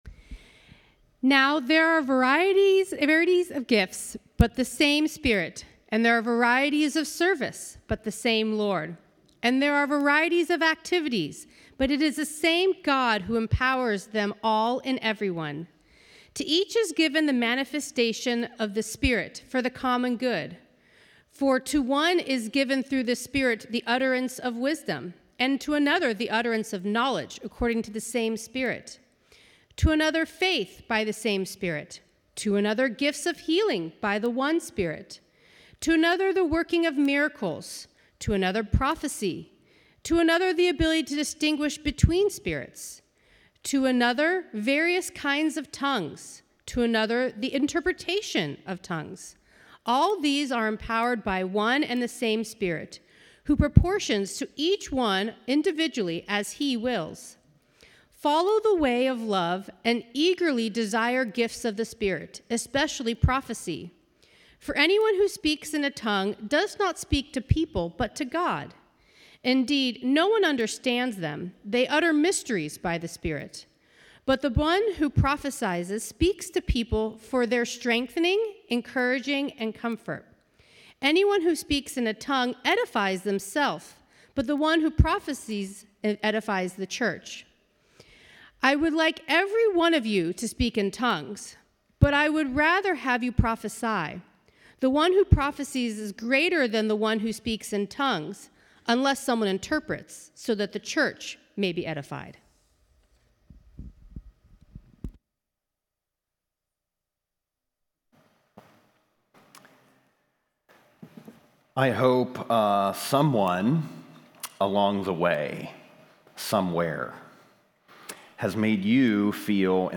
A message from the series "Called Out."